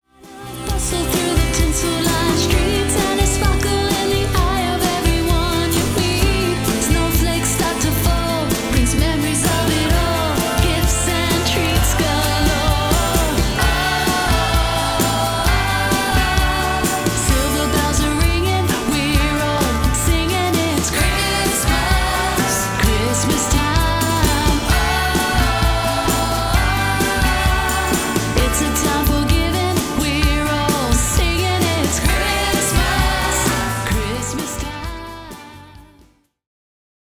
Christmas Single
Vocals/Guitars
Bass
Drums
Backing Vocals